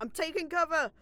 Voice Lines / Combat Dialogue
Becca Im taking cover.wav